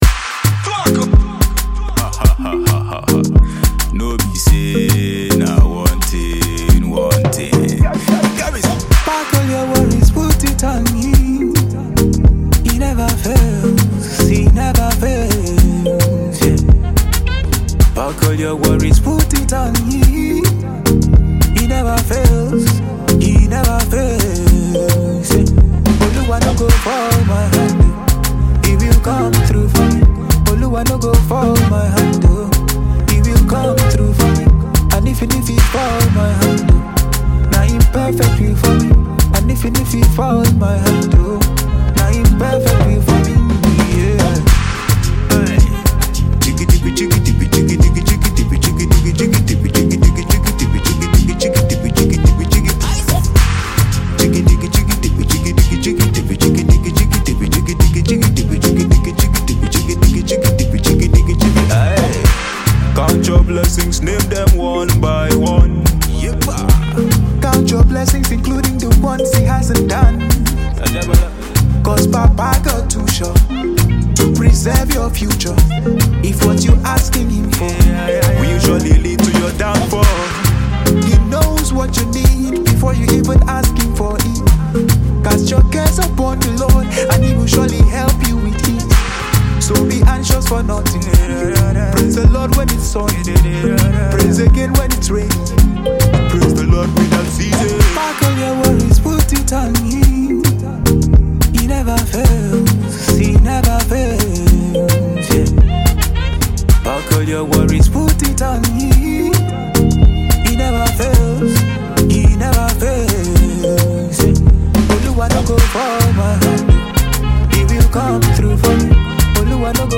Gospel Versatile vocalist
This afrobeat hit song